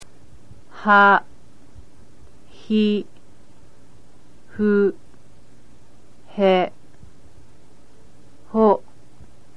The H* sound is much harder than the western H sound.